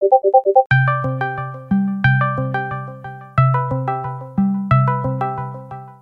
Discord spam
discord-spam.mp3